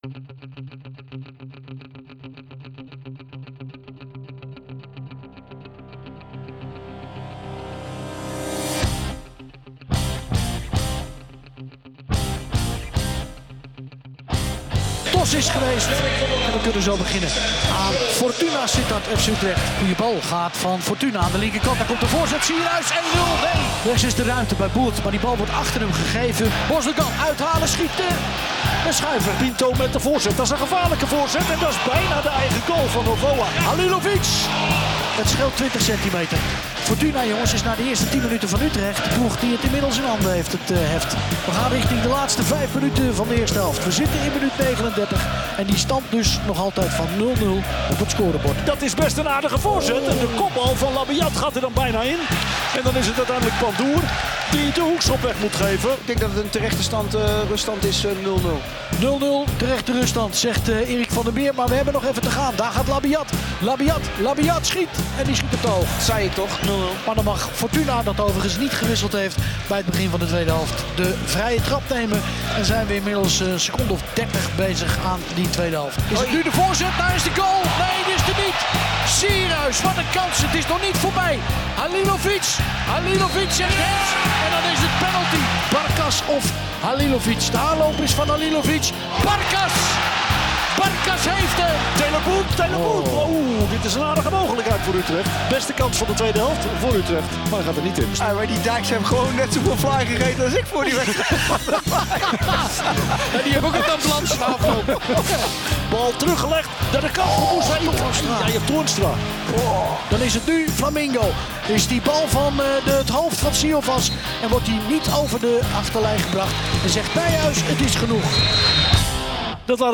Luister nu de hoogtepunten, interviews en analyses van deze wedstrijd terug via jouw favoriete podcast-app.